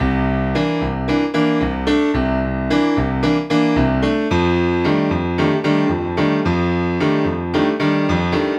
Track 15 - Piano.wav